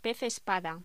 Locución: Pez espada
voz